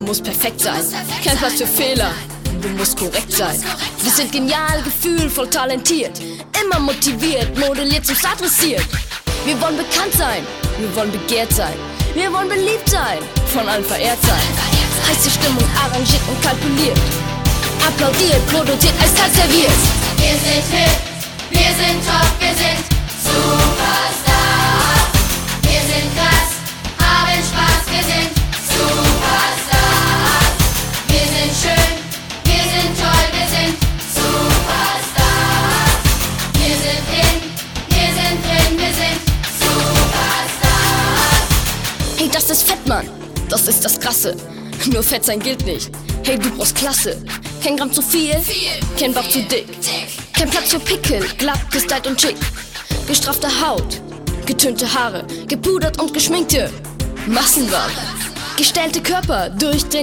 Musicals
Ein Musical für Kids & Teens